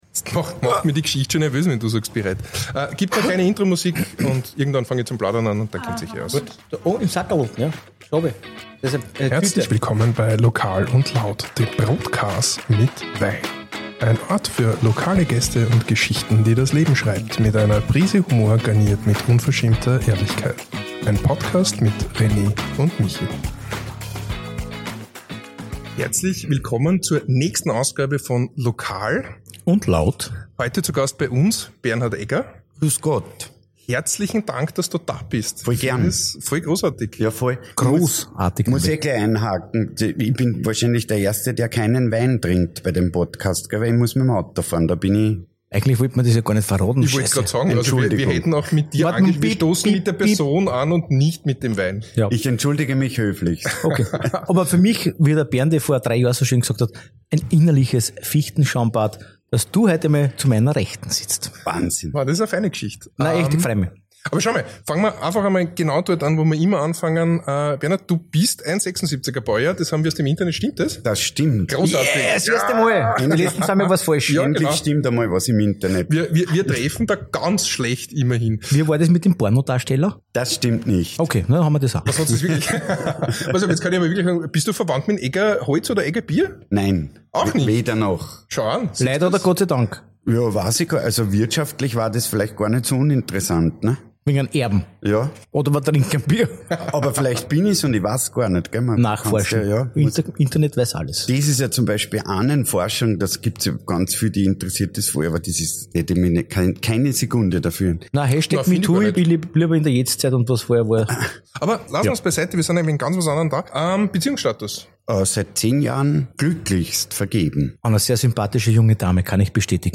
Ein inspirierendes Gespräch über Leidenschaft, Durchhaltevermögen und die Kraft lokaler Netzwerke.